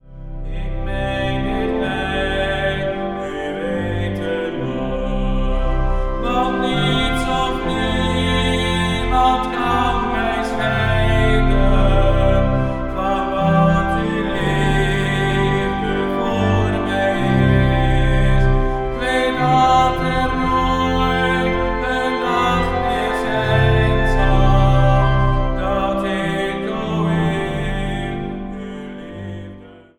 Zang | Solozang